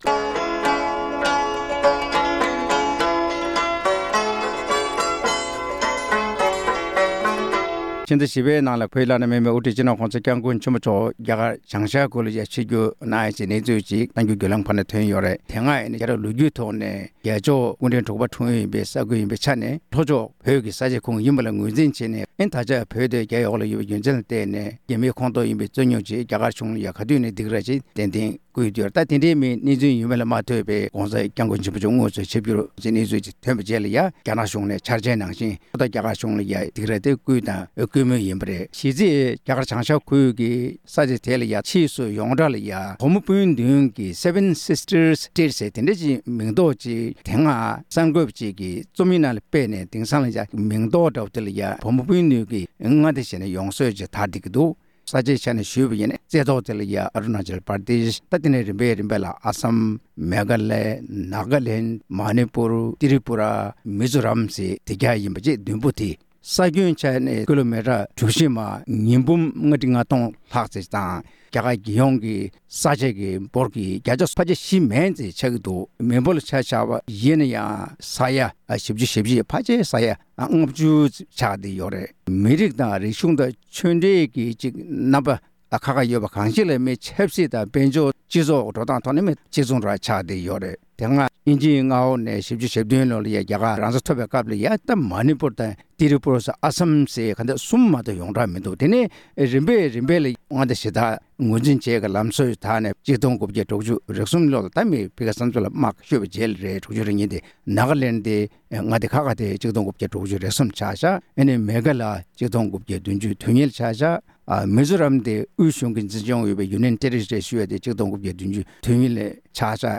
གླེང་མོལ་གནང་བར་གསན་རོགས་ཞུ།།